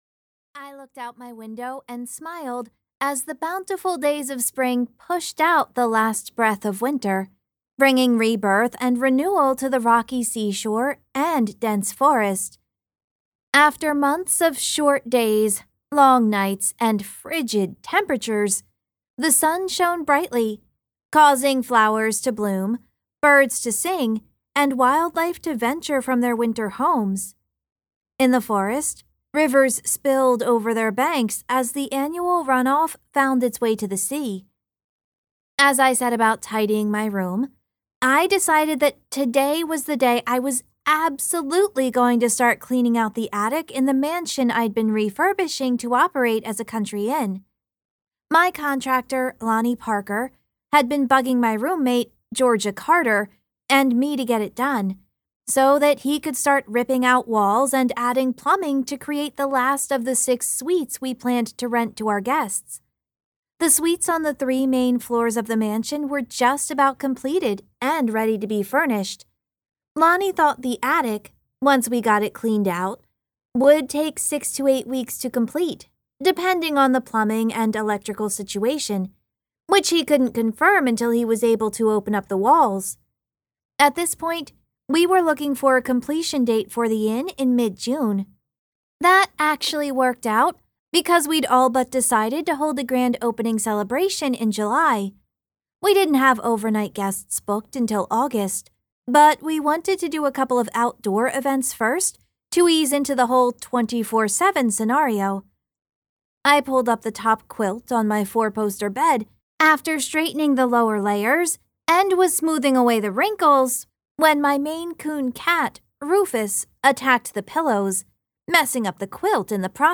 • Audiobook
Book 4 Retail Audio Sample The Inn at Holiday Bay Answers in the Attic.mp3